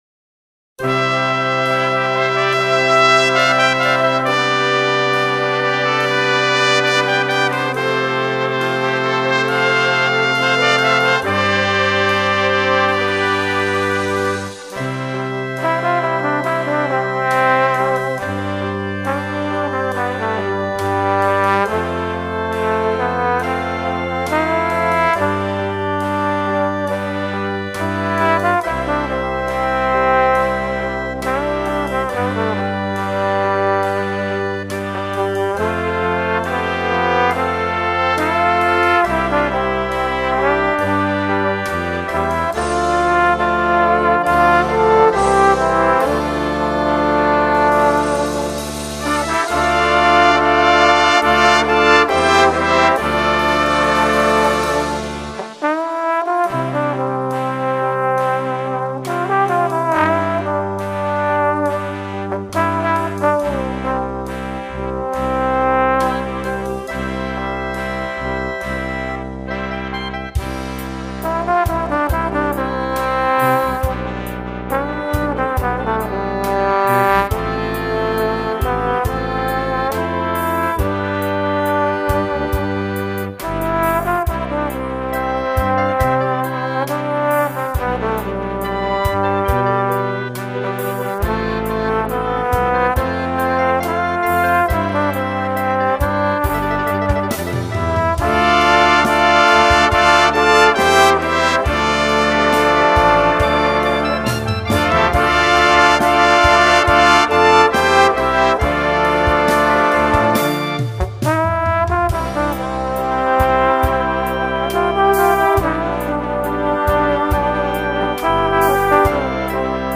Dychová hudba Značky
Inštrumentalne
Slow Zdieľajte na